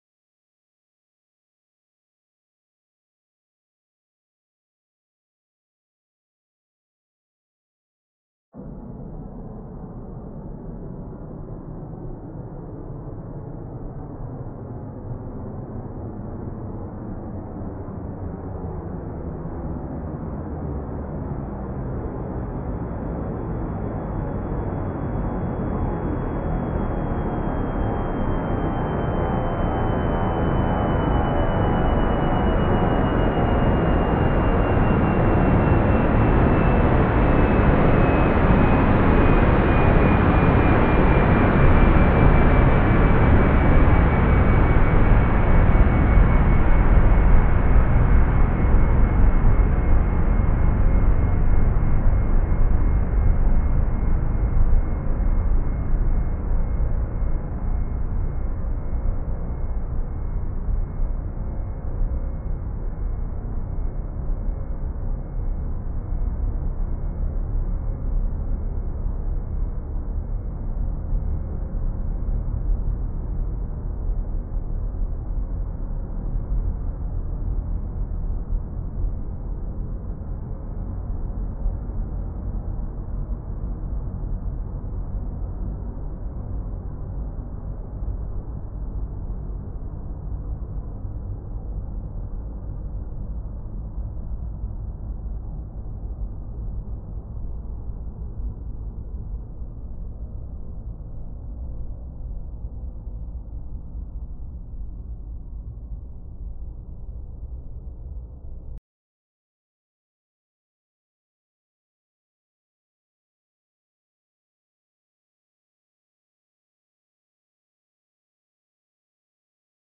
Auralization of atmospheric turbulence-induced amplitude fluctuations in aircraft flyover sound based on a semi-empirical model | Acta Acustica
05_baseline_synthesis.mp3 (10
)   Baseline Synthesis